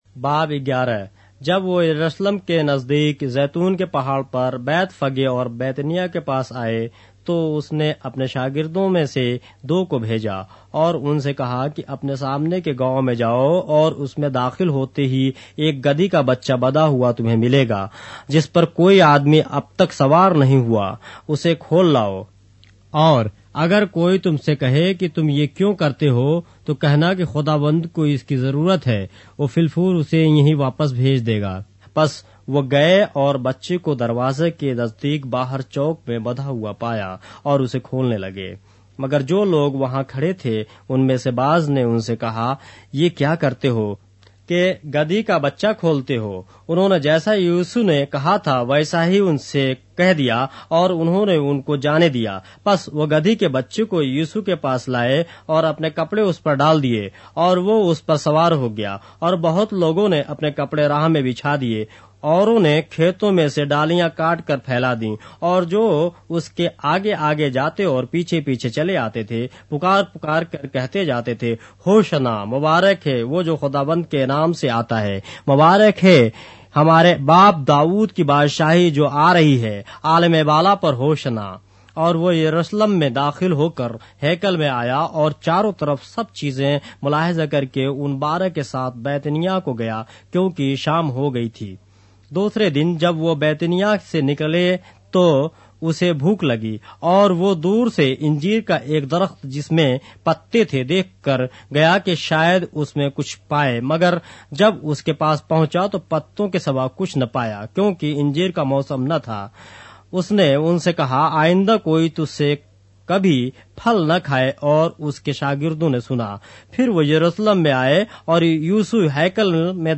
اردو بائبل کے باب - آڈیو روایت کے ساتھ - Mark, chapter 11 of the Holy Bible in Urdu